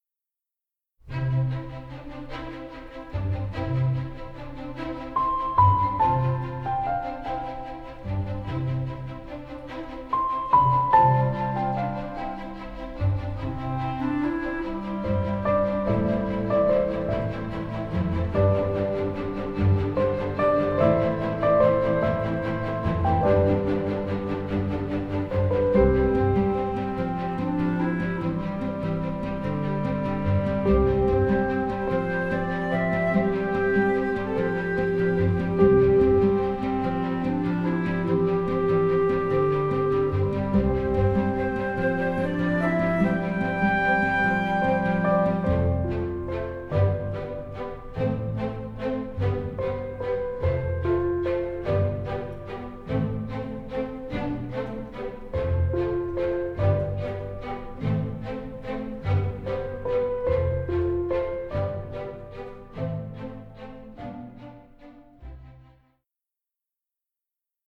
orchestral score which offers extremely wide range of colors